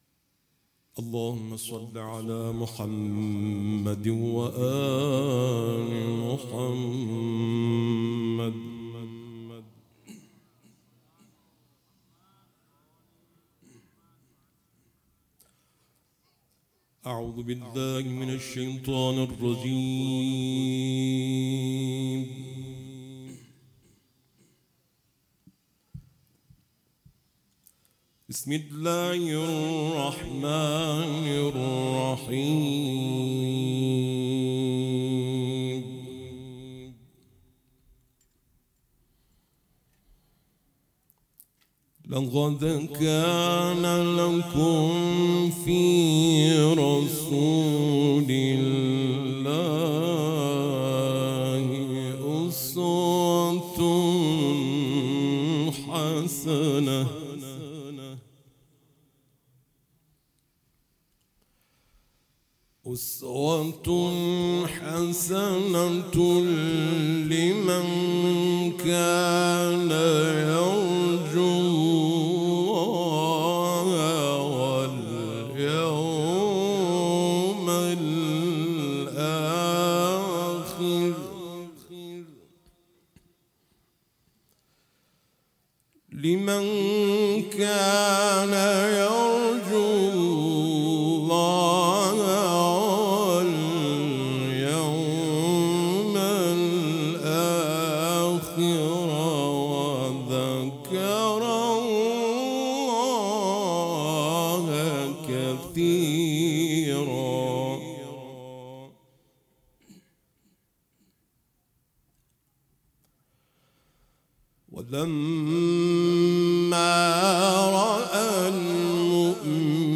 برچسب ها: تلاوت قرآن ، عزاداری حسینی ، هیئت عزاداری ، چهارمحال و بختیاری ، تاسوعا